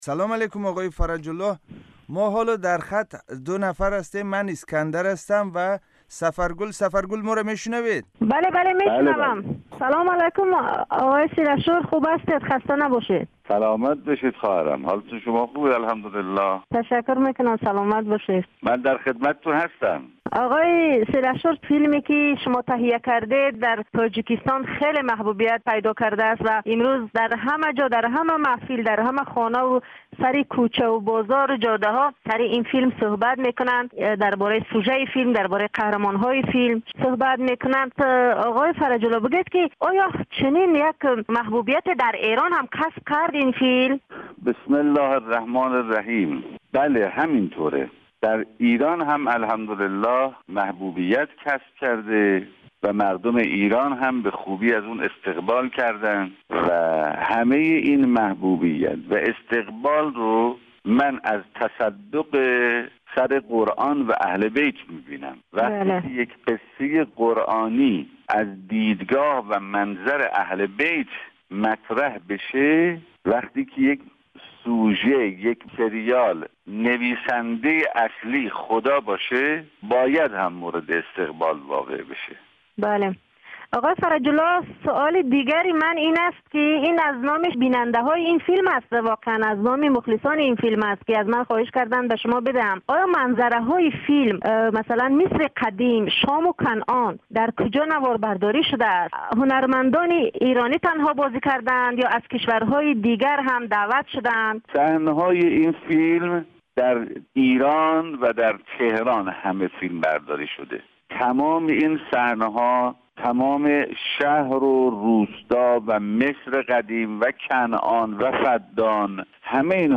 Мусоҳиба